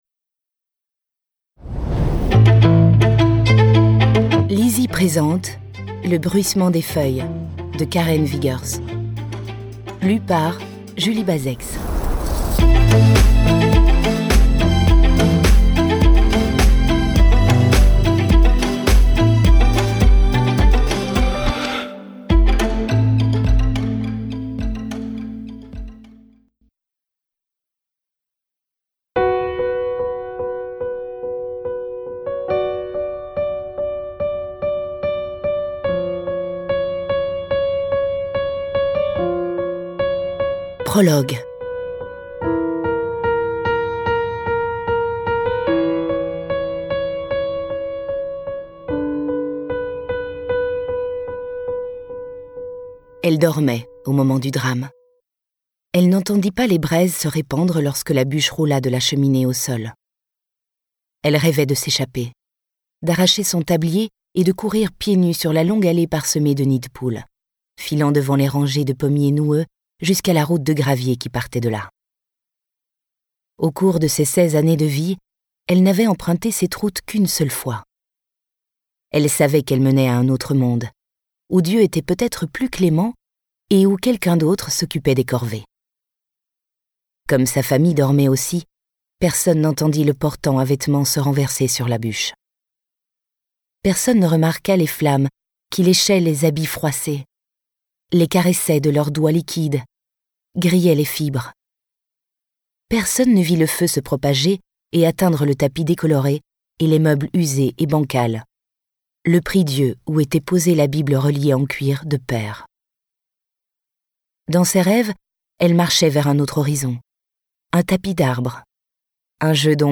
Extrait gratuit - Le Bruissement des feuilles de Karen VIGGERS